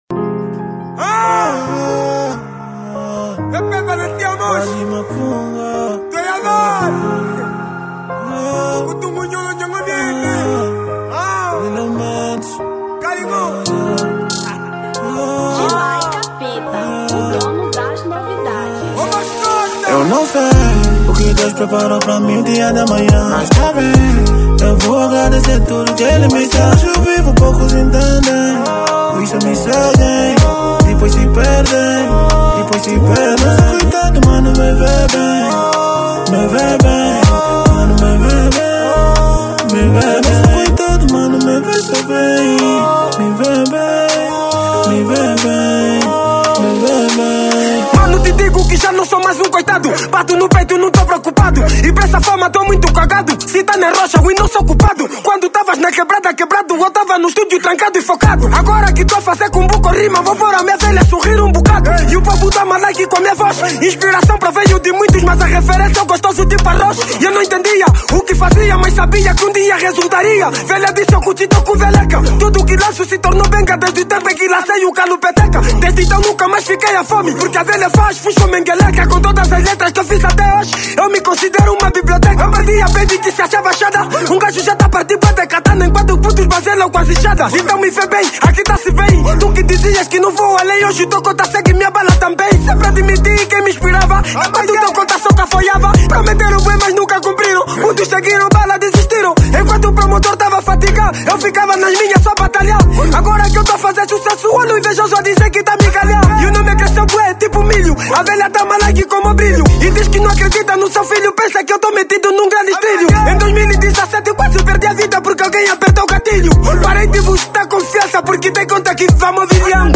Kuduro 2023